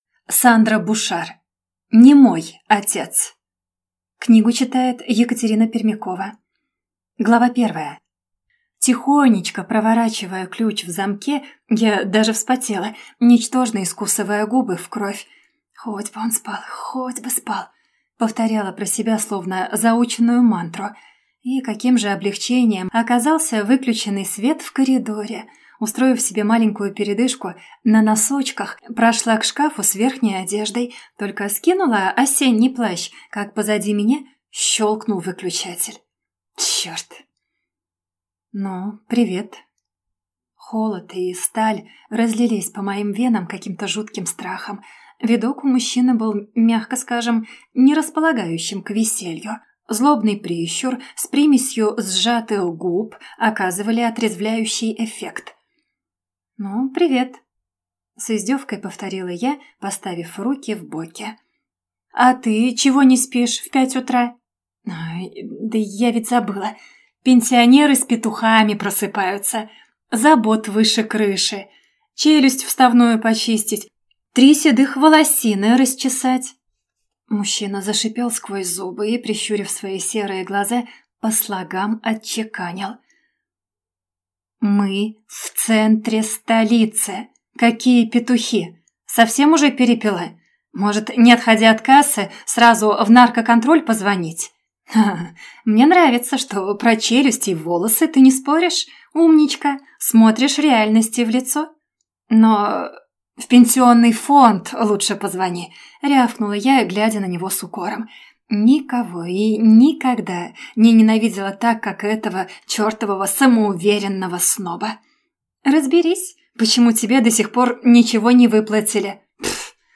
Аудиокнига Не мой отец | Библиотека аудиокниг
Прослушать и бесплатно скачать фрагмент аудиокниги